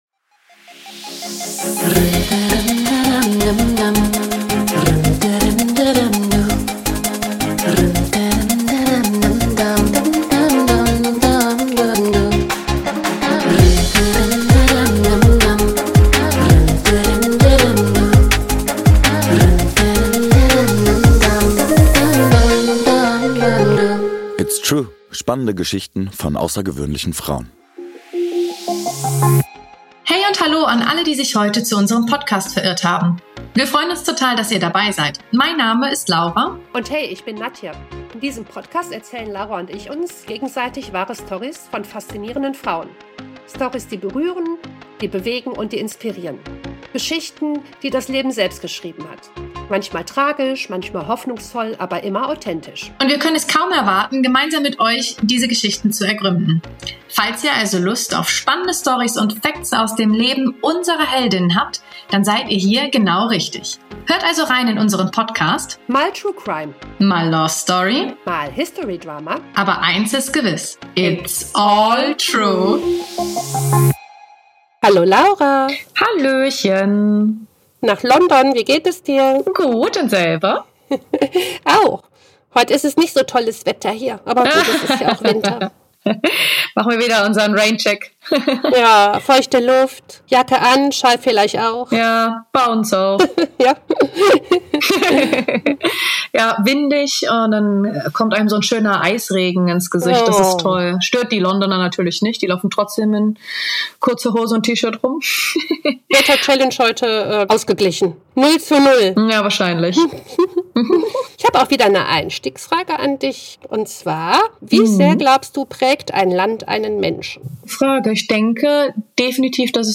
Leider ist die Tonqualität im ersten Viertel etwas schlechter als sonst.